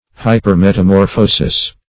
Search Result for " hypermetamorphosis" : The Collaborative International Dictionary of English v.0.48: Hypermetamorphosis \Hy`per*met`a*mor"pho*sis\, n. [Hyper- + metamorphosis.]